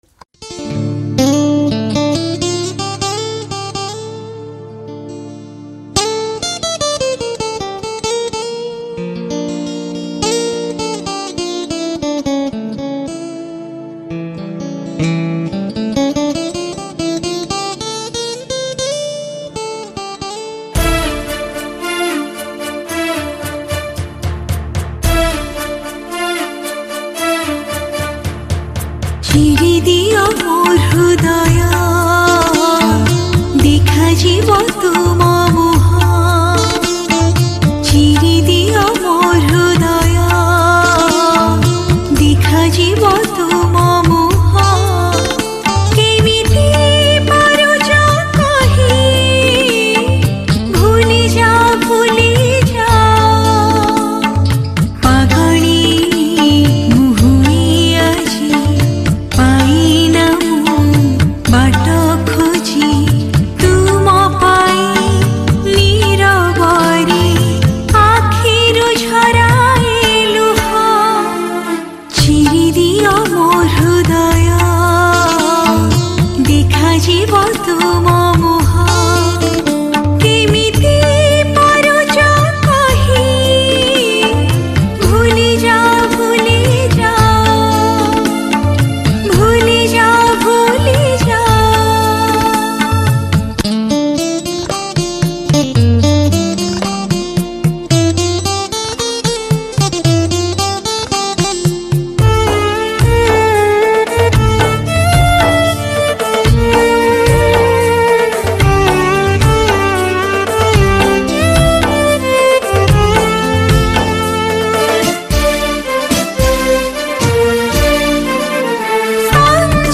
Sad Odia Song